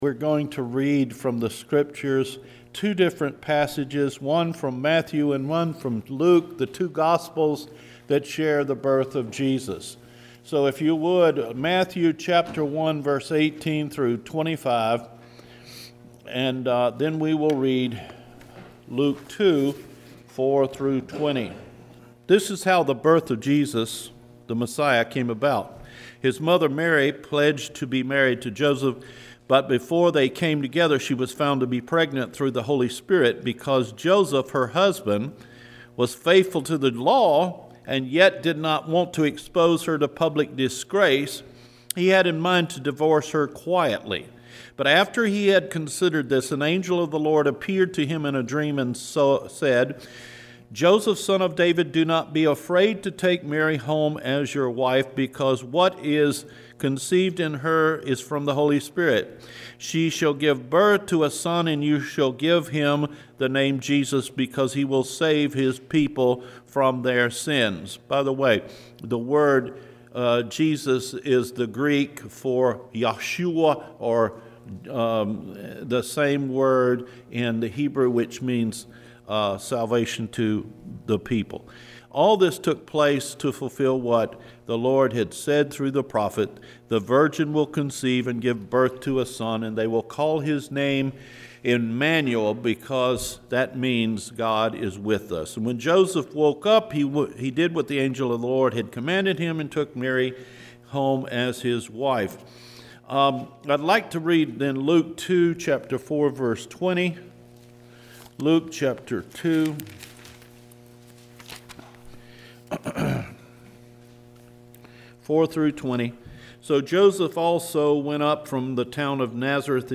The Christmas Story – December 23 Sermon